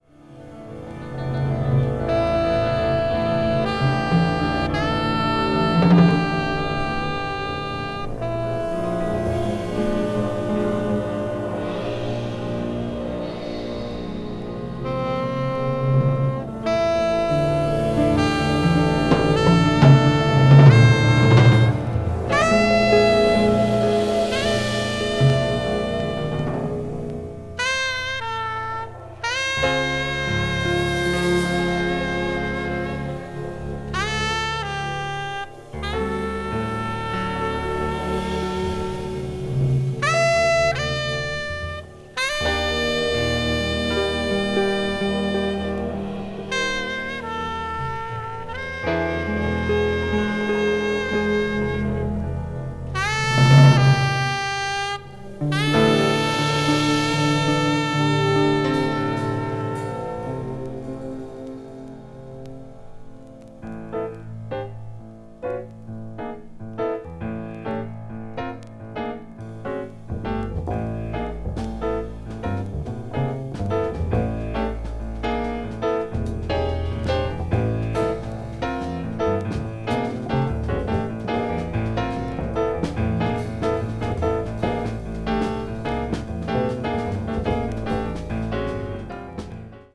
avant-jazz   deep jazz   post bop   spiritual jazz